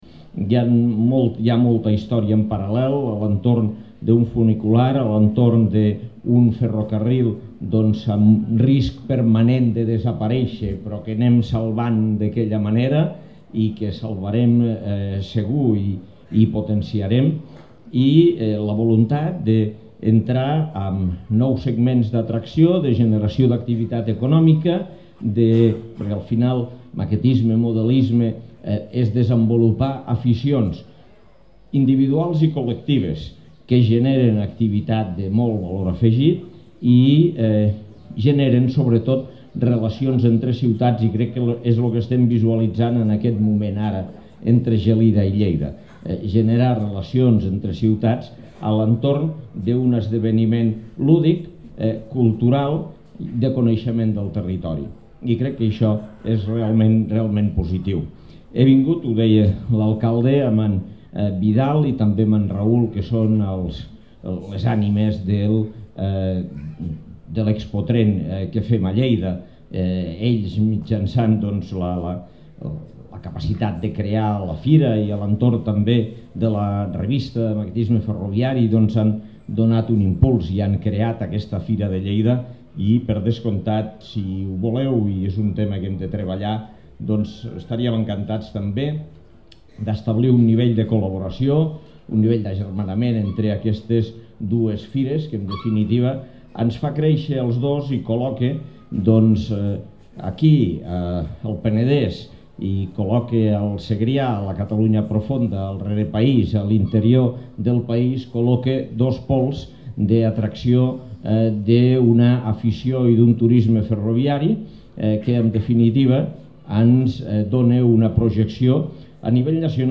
tall-de-veu-de-lalcalde-de-lleida-angel-ros-amb-motiu-de-la-seva-visita-a-funifira-de-gelida